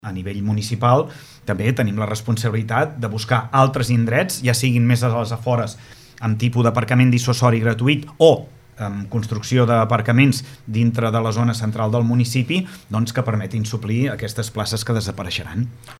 Els dos polítics, l’alcalde per Esquerra Republicana (ERC) Lluís Puig, i el socialista Josep Coll, cap de l’oposició, han analitzat l’actualitat municipal als micròfons de Ràdio Capital de l’Empordà.